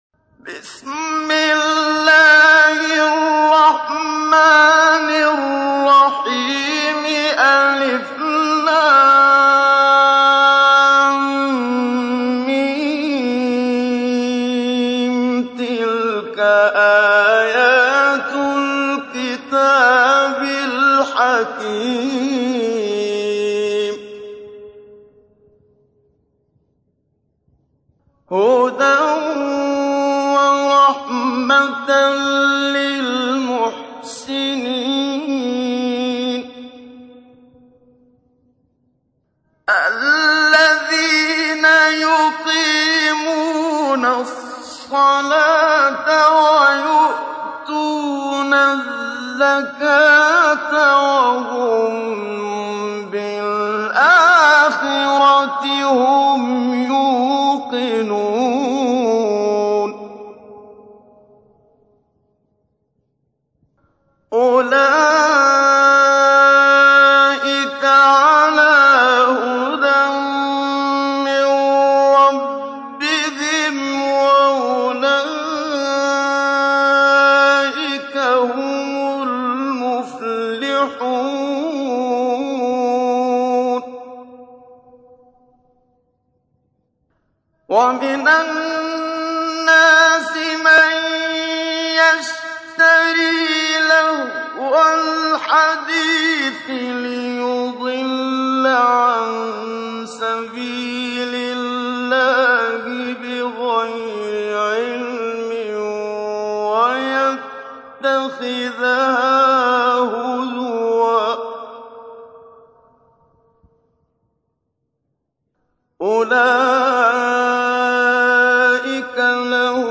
تحميل : 31. سورة لقمان / القارئ محمد صديق المنشاوي / القرآن الكريم / موقع يا حسين